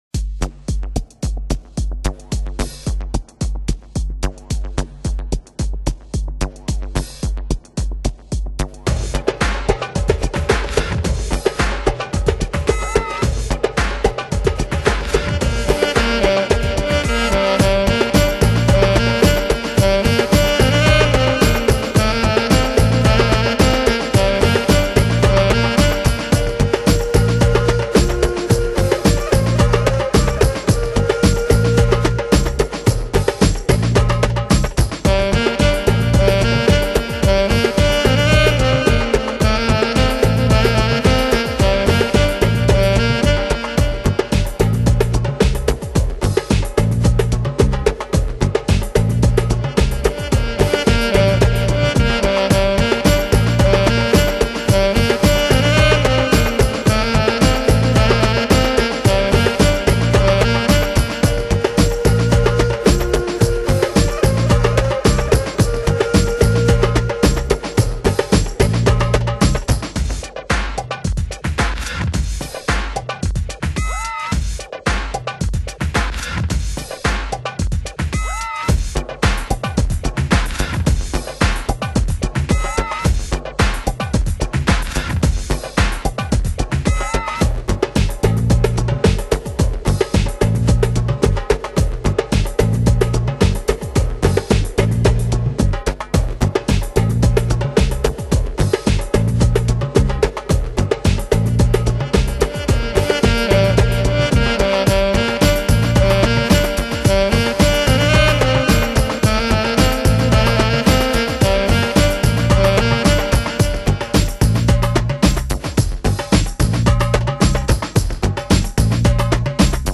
Genre: Downtempo, Chillout, Lounge